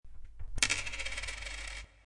Index of /html/coin_sounds/